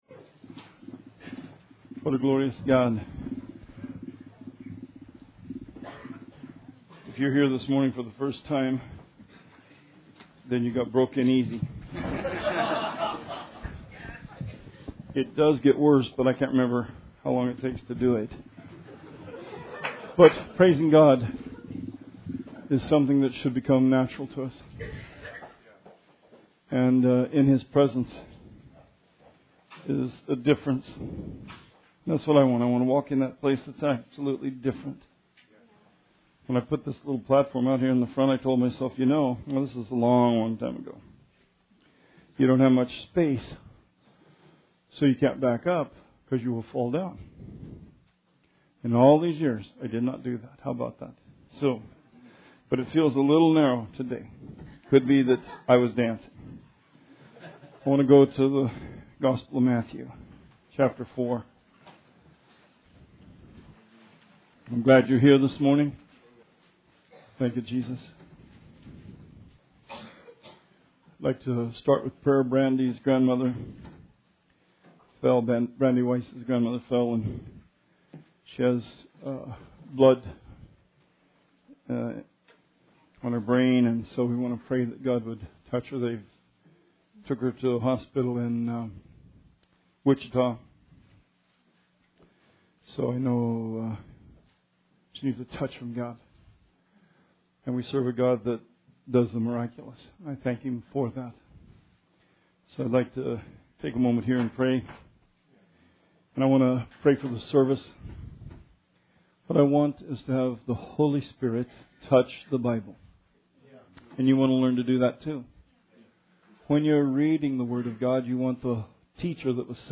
Sermon 12/4/16